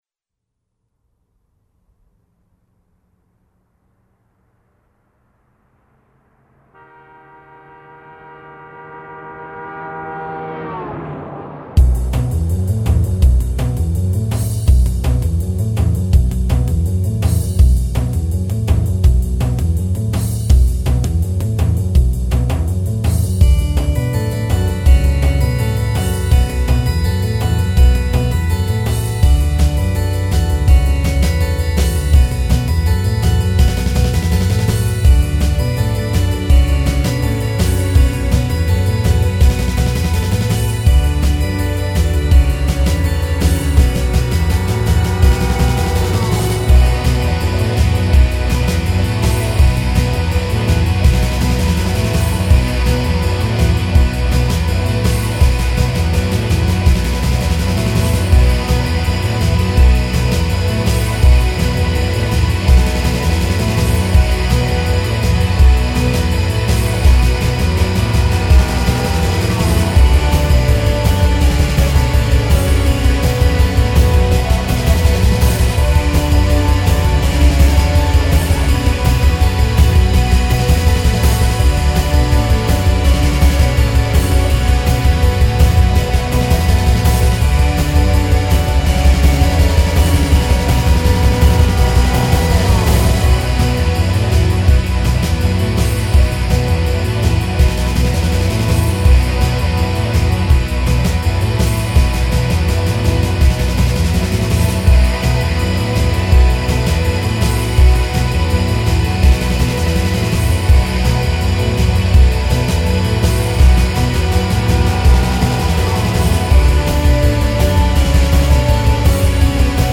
Fast Melodic Rock
'05 Remix EQ - Live Guitar